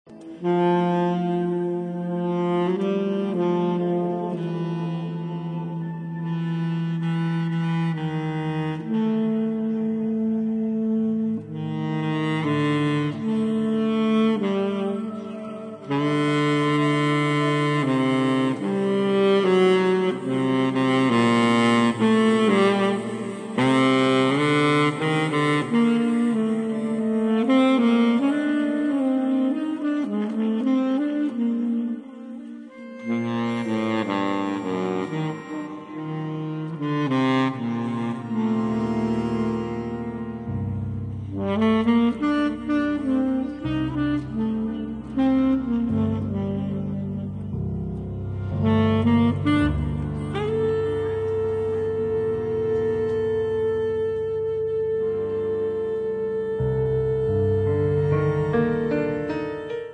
pianoforte
sax soprano e baritono
accordion
electronics
Improvvisazione europea d'alto lignaggio, ma